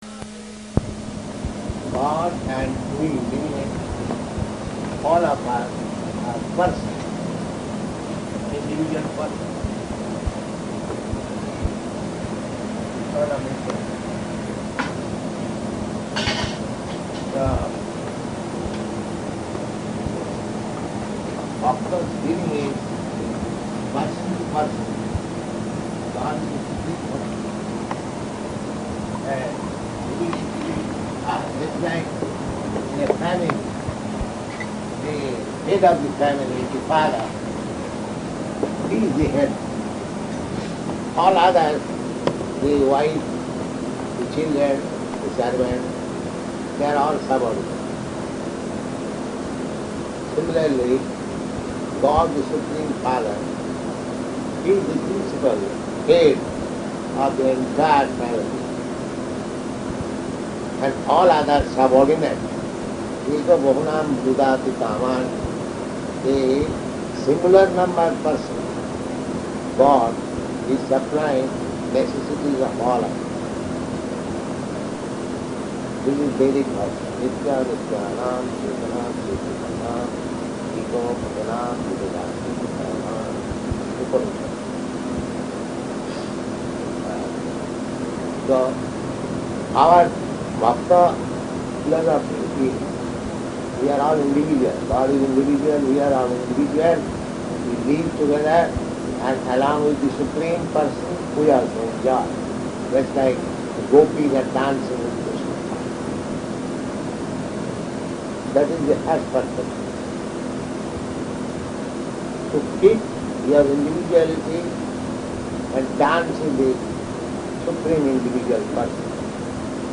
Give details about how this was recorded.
Location: Arlington